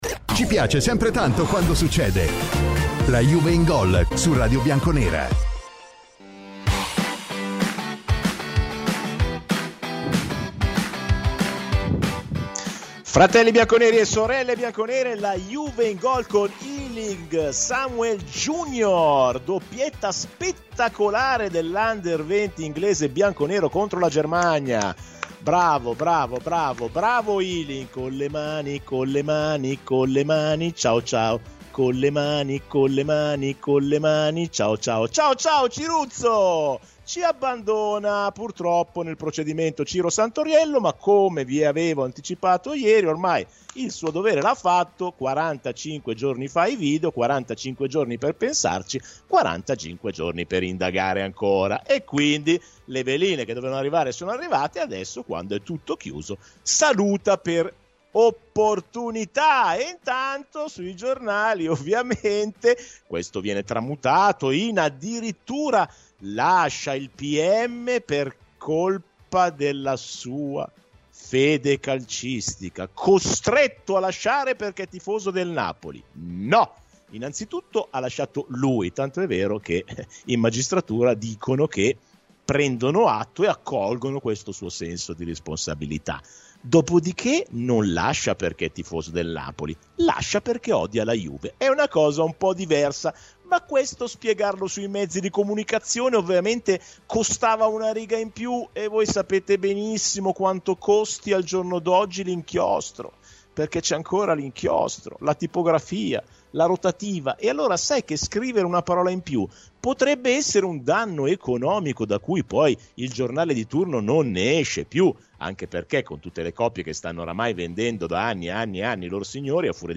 è stato ospite oggi di “La Juve in Gol” su Radio BiancoNera